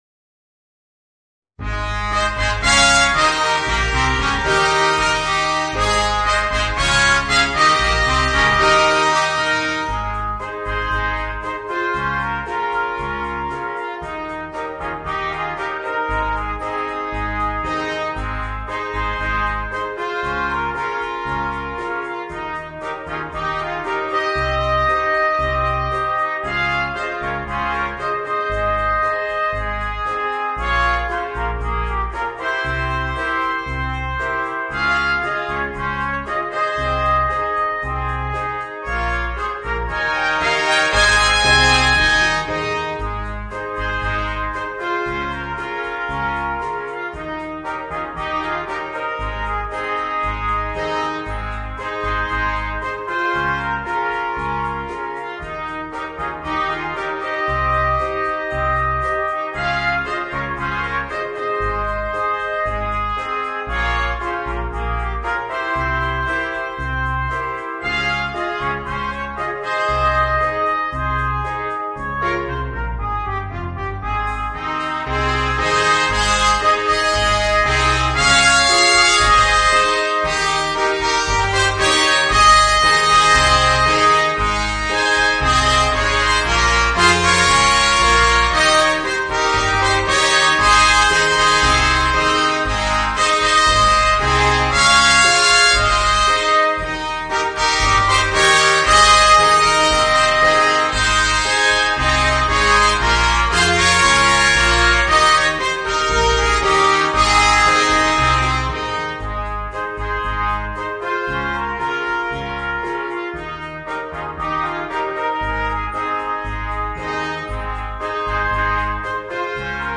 Voicing: 4 Trumpets